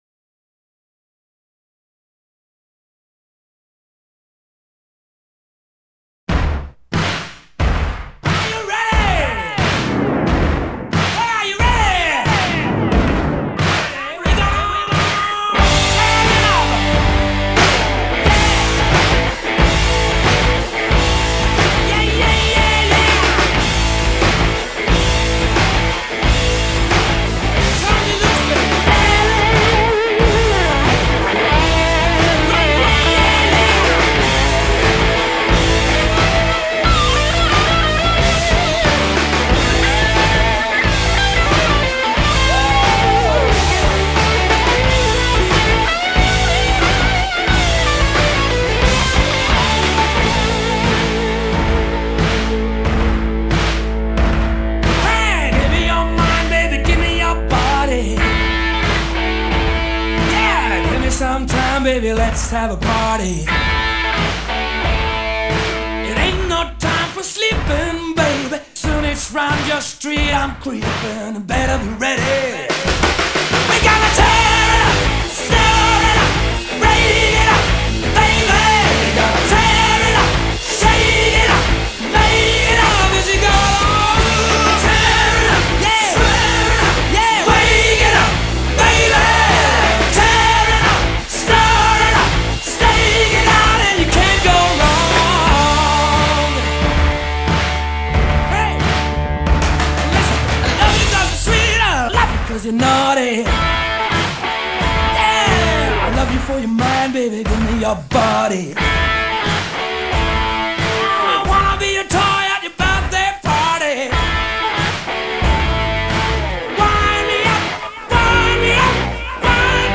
اجرای زنده افسانه‌ای
گروه راک بریتانیایی